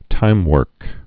(tīmwûrk)